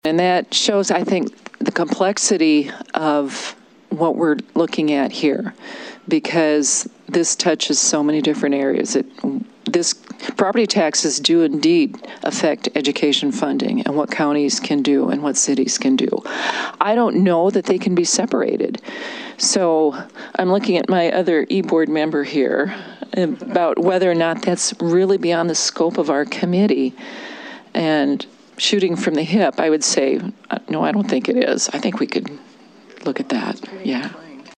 The “Study Committee on Property Tax Structure and Tax Burden” held their second meeting of the interim in Pierre.
Committee co-chair District 24 Senator Mary Duvall of Pierre said they have to consider a lot of other issues connected to property taxes.